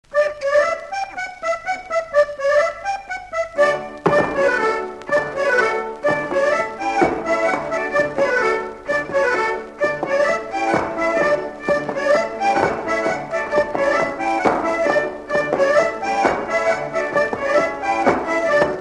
Mémoires et Patrimoines vivants - RaddO est une base de données d'archives iconographiques et sonores.
danse de la guernoïe
Couplets à danser
Pièce musicale inédite